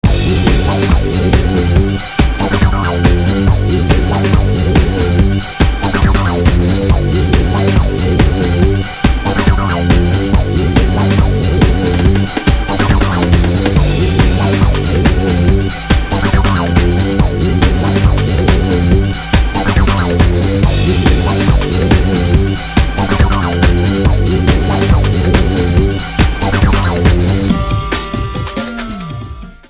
dansmuziek
een intense hybride van speelse dans en rauwe rock.